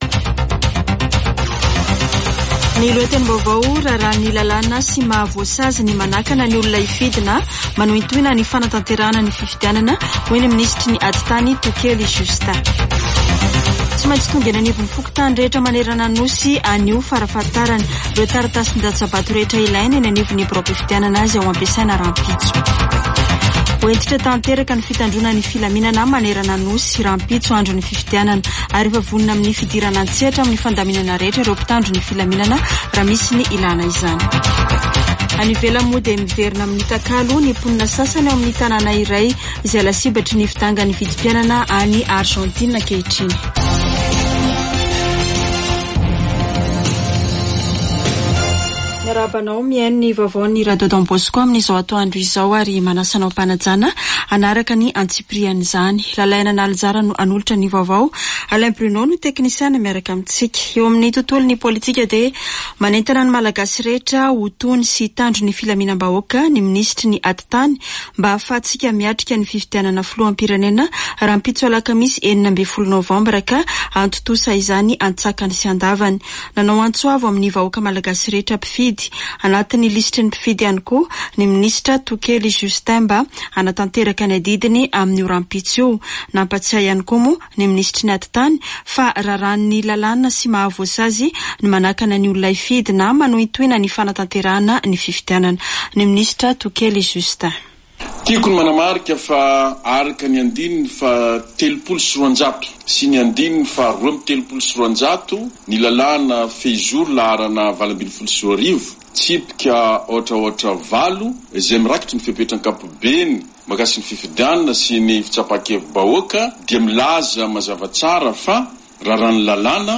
[Vaovao antoandro] Alarobia 15 nôvambra 2023